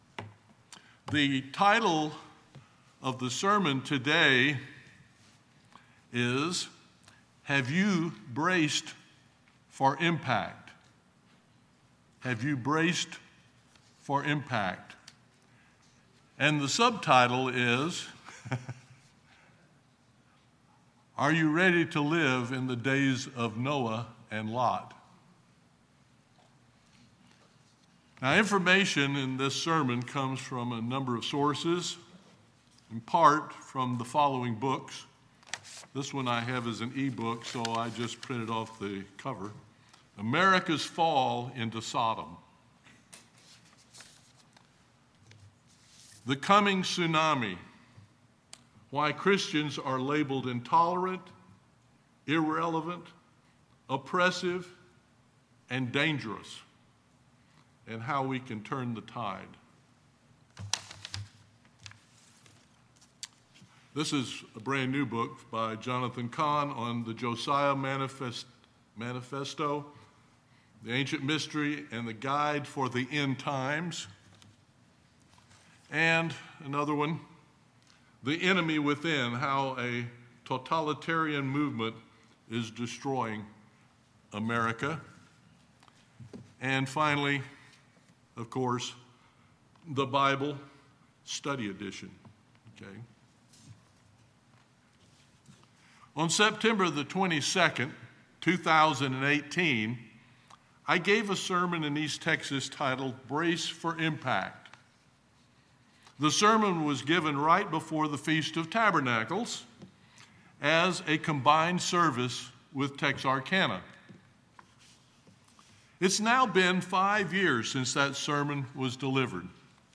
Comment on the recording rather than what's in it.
Given in East Texas